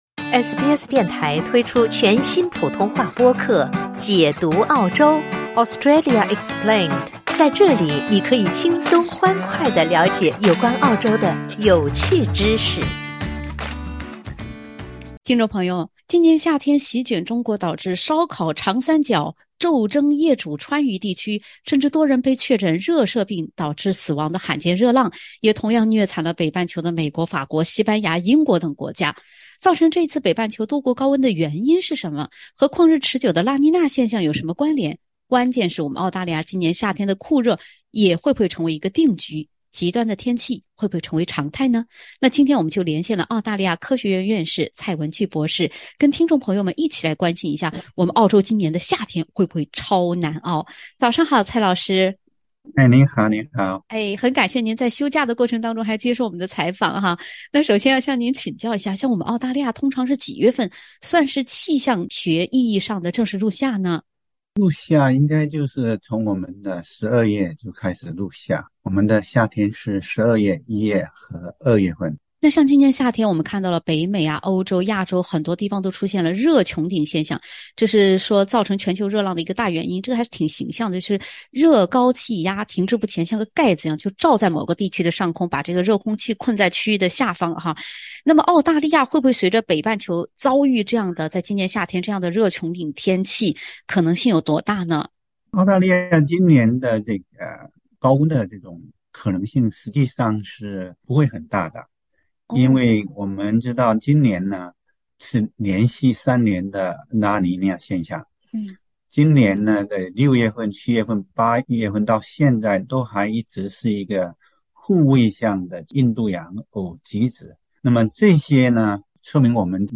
北半球多国今夏高温难耐，澳大利亚今年夏天也会热得“煎熬”吗？（点击封面图片，收听完整采访）